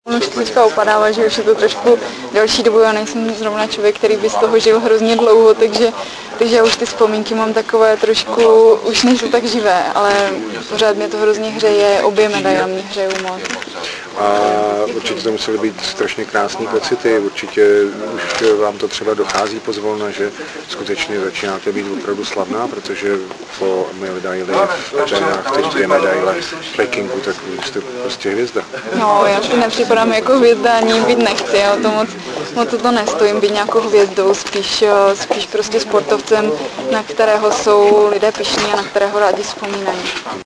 Rozhovor premiéra s Kateřinou Emmons